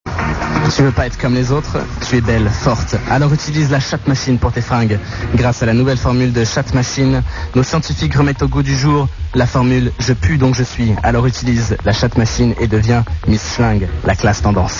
Fausse Pubs : La chatte machine